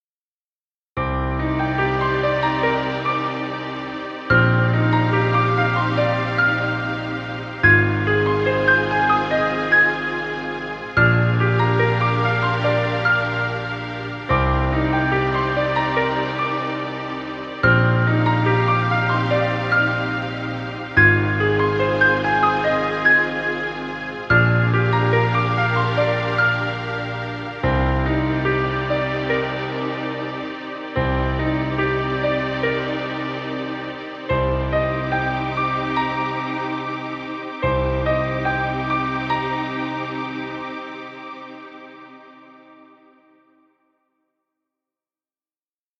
Romantic cinematic music.